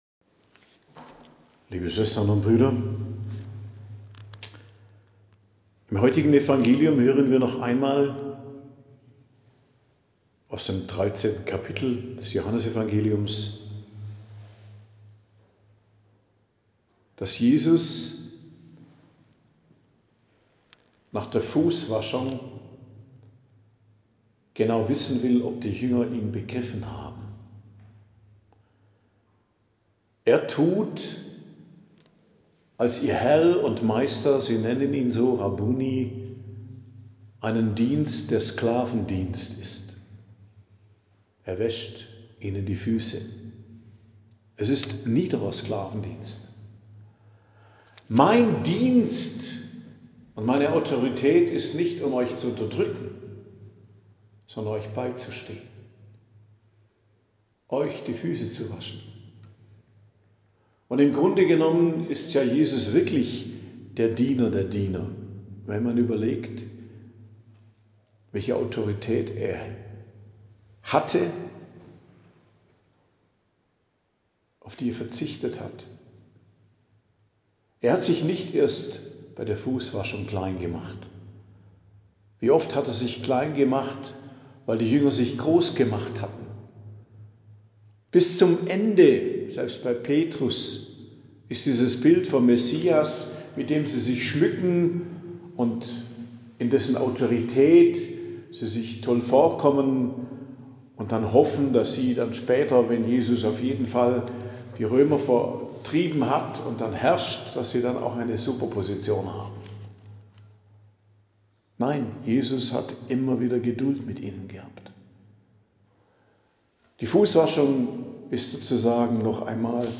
Predigt am Donnerstag der 4. Osterwoche, 15.05.2025